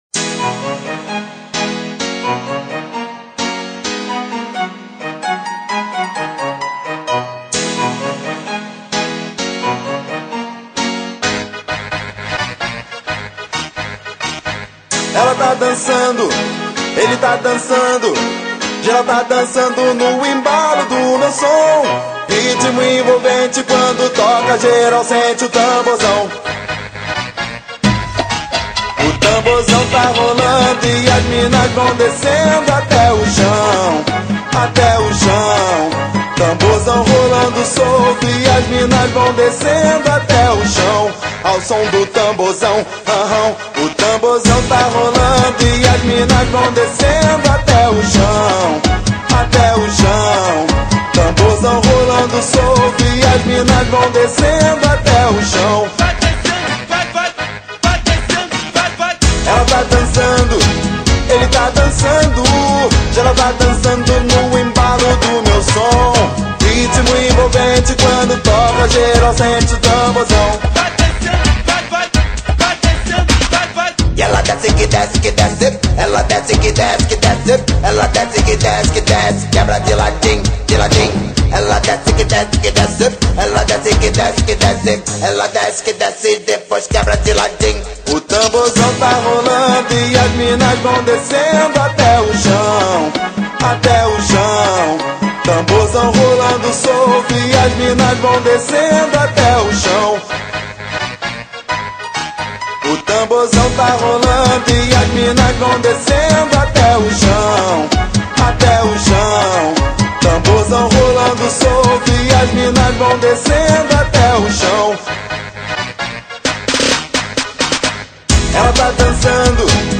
Funk Para Ouvir: Clik na Musica.